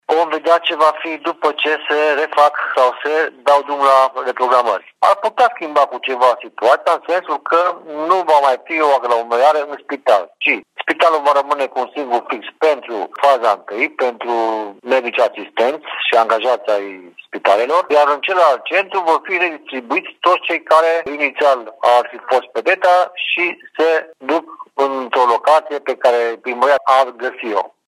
Amenajarea unui nou centru, de data asta de către primărie, ar putea ajuta campania de vaccinare pe viitor, susține subprefectul Mircea Băcală.